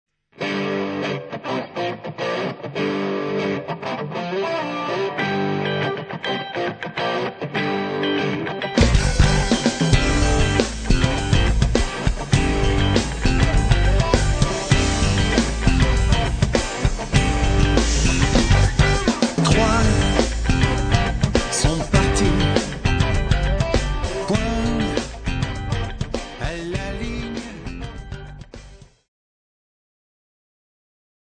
Groupe Rock PAU